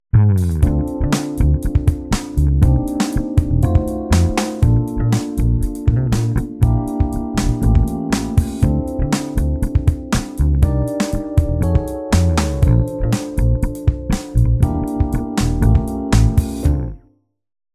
In these examples, our chord we are focusing on is used as the V7 going to the main key we started in indicated by the red arrows.